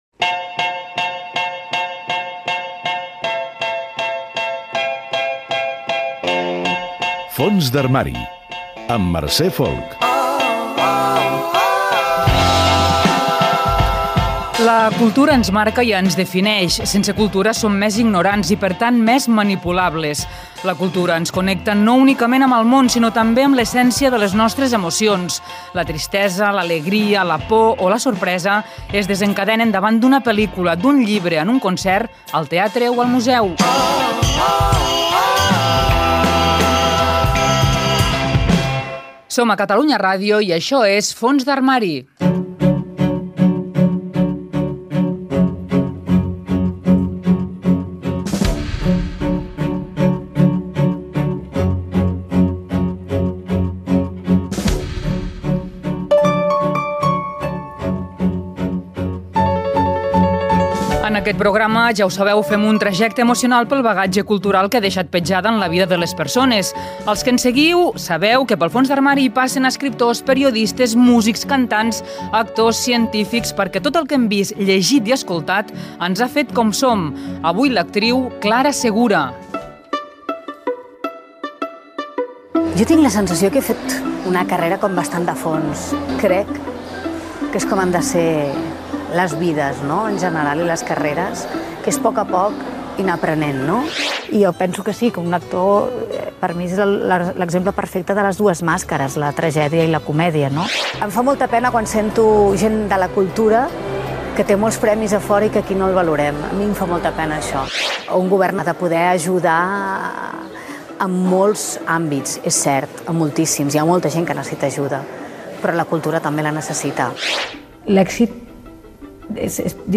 Indicatiu del programa, objectiu, recull de declaracions de Clara Segura, fragment d'una interpretació teatral d'Antígona, entrevista a l'actriu
Entreteniment